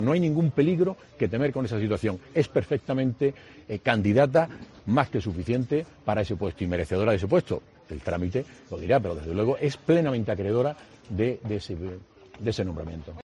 "Es perfectamente candidata y merecedora de ese puesto. Es plenamente acreedora de ese lugar", ha recalcado antes de asistir a una entrega de premios de la Asociación de Comunicadores e Informadores Jurídicos, y horas antes de que el CGPJ analice la candidatura de Delgado.
Las primeras declaraciones a los medios de comunicación de Campo como ministro han tenido lugar en plena polémica por el encontronazo del CGPJ con el Ejecutivo, al criticar ayer unas palabras del vicepresidente segundo, Pablo Iglesias.